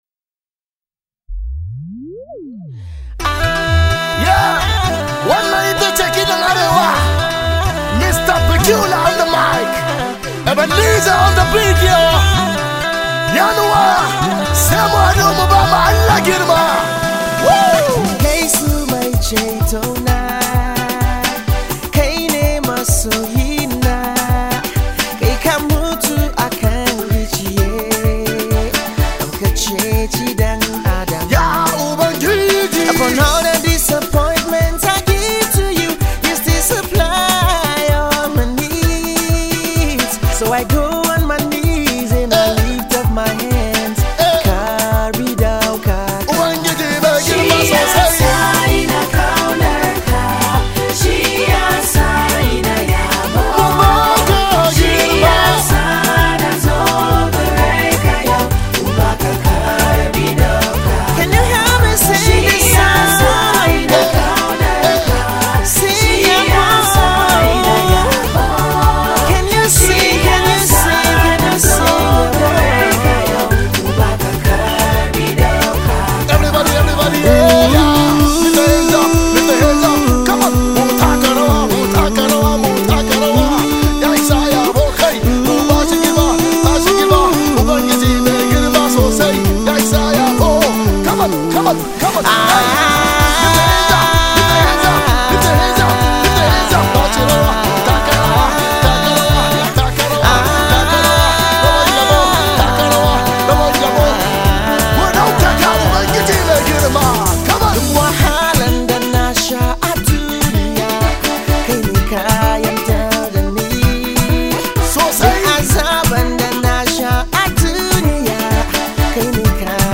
Gospel Track